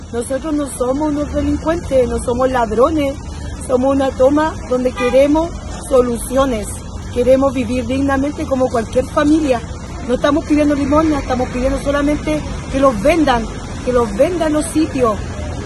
Los desalojados por Carabineros dijeron que ellos esperaban conversar con Forestal Arauco para buscar la posibilidad de realizar la compra del terreno de poco más de 9 hectáreas, aseguró una mujer.